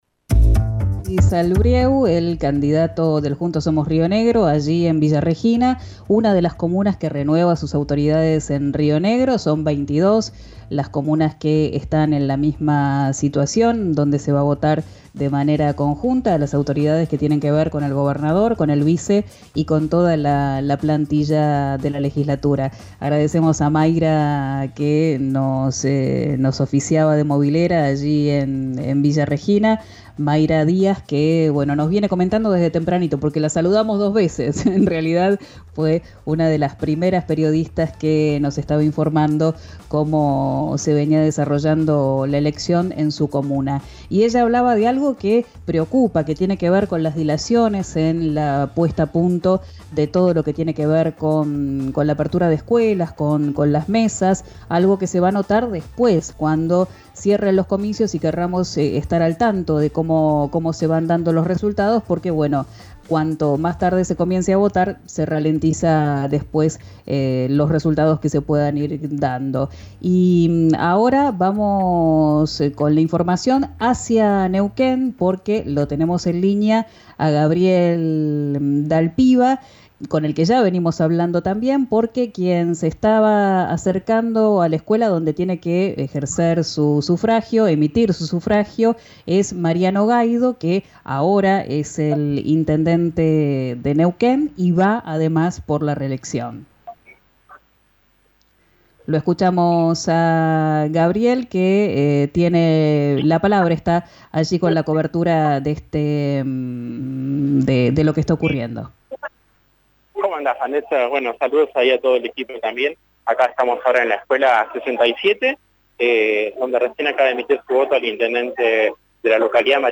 El intendente de Neuquén y candidato a la reelección, emitió su voto esta mañana y se tomó un tiempo para hablar con los medios. Destacó la importancia de esta nueva modalidad de voto y sobre cómo Neuquén se convirtió en un ejemplo nacional.
Escuchá a Mariano Gaido en RÍO NEGRO RADIO: